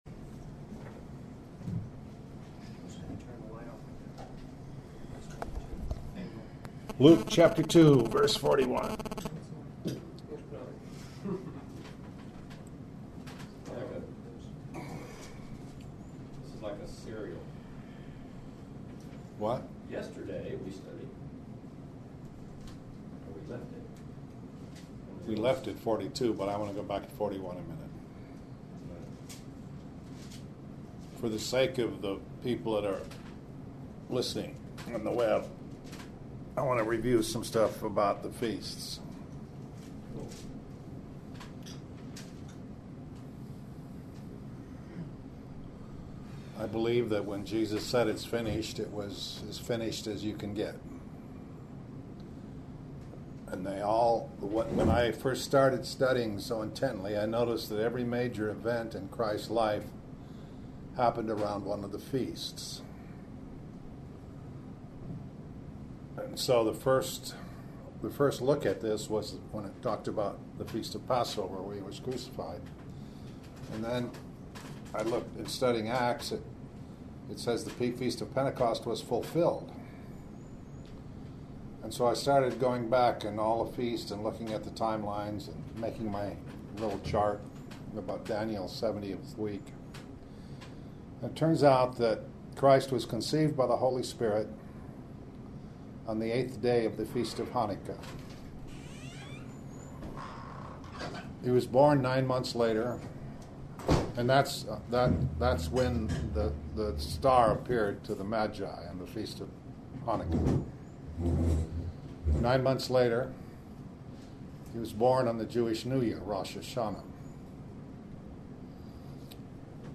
Morning Bible Studies